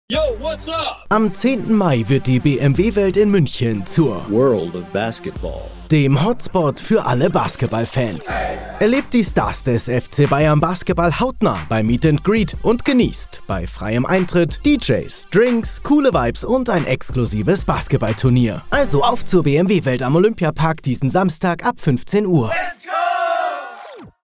Professioneller Sprecher & Moderator
Mein hauseigenes Studio in Broadcast-Qualität sorgt für exzellente Ergebnisse bei vielfältigen Projekten.
3 | RADIO-SPOT
SPOT_BMW_WELT_Samstag.mp3